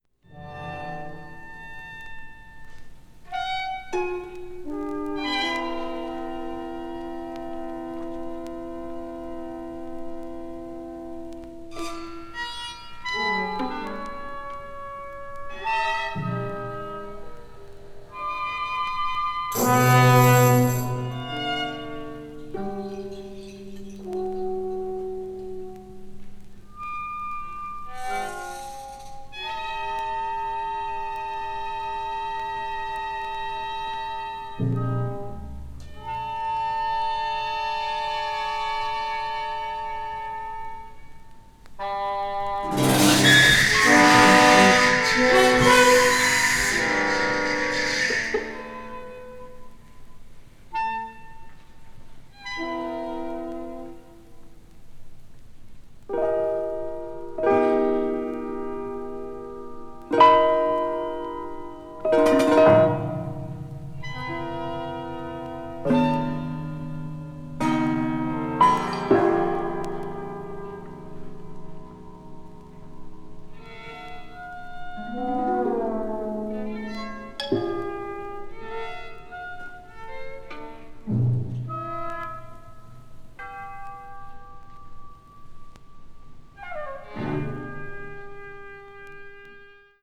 media : EX/EX(わずかにチリノイズが入る箇所あり)
臨場感あふれるライヴ録音。